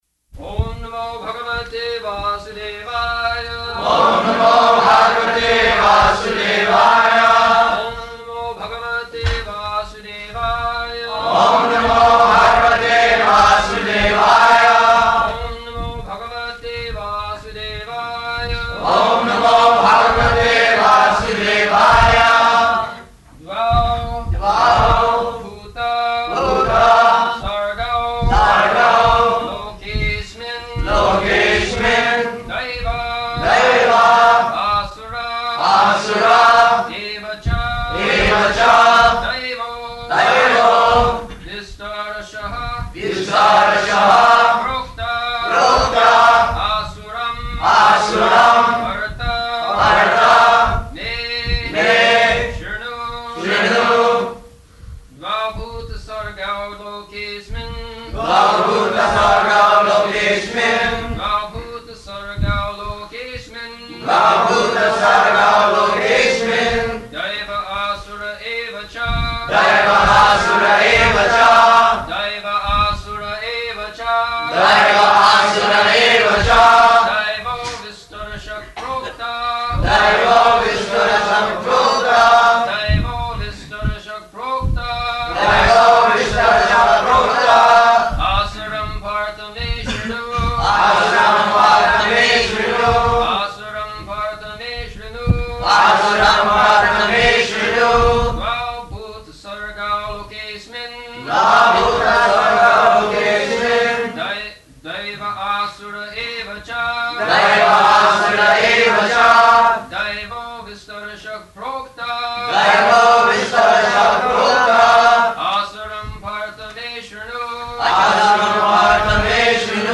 February 2nd 1975 Location: Honolulu Audio file
[devotees repeat] [leads chanting of verse, etc.]